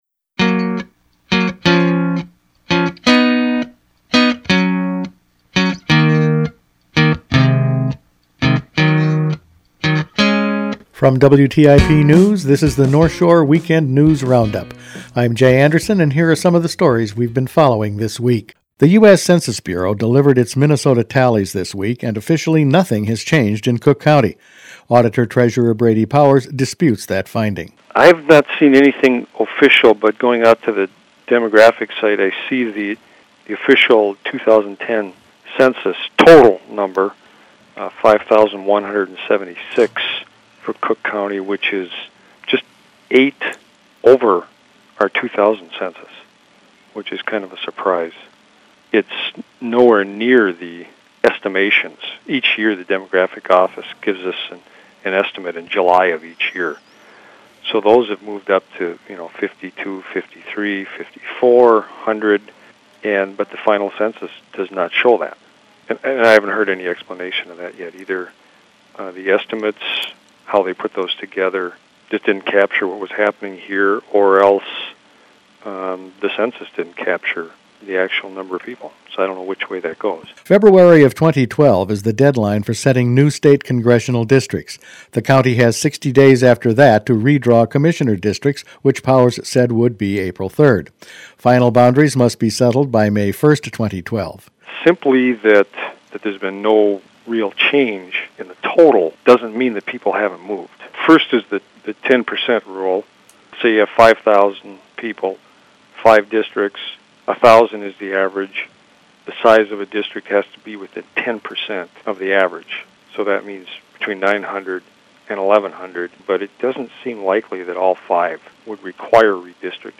Each weekend WTIP news produces a round up of the news stories they’ve been following this week. Redistricting, wolves and dogs, a new prescription drug plan and road restriction  were all in this week’s news.